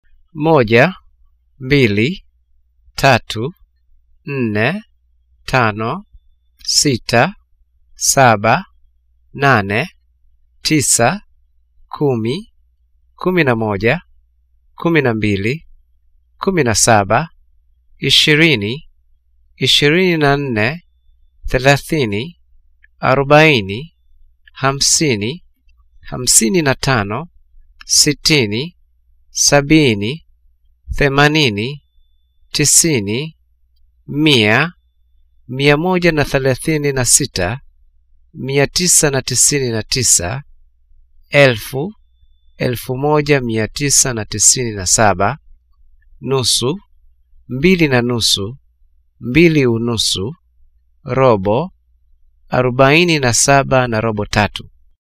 Du kan også klikke på det lille MP3 logo over hvert afsnit for at høre ordene udtalt!